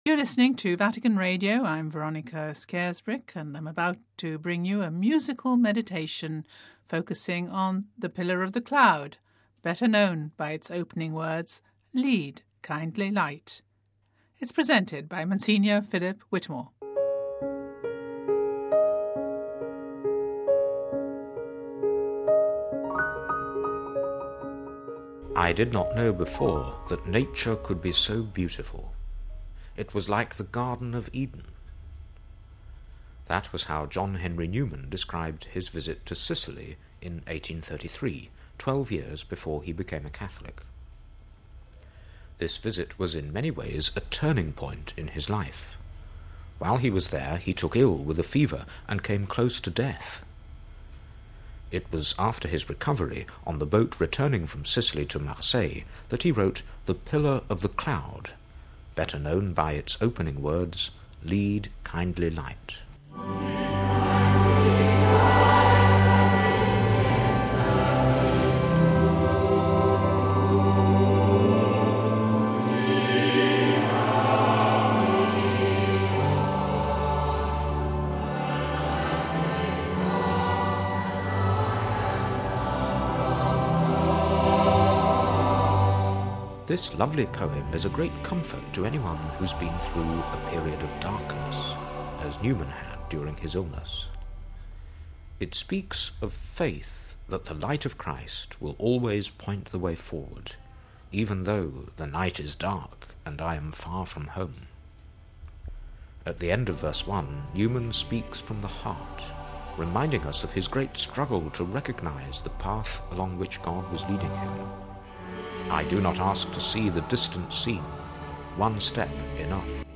'Lead, kindly light': a musical meditation